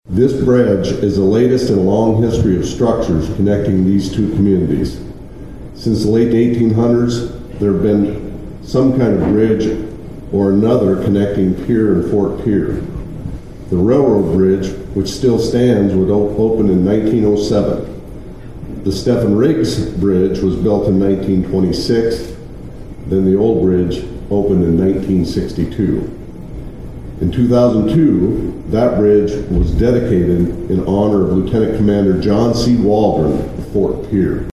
During the ribbon cutting, South Dakota Governor Larry Rhoden shared some history about the structures.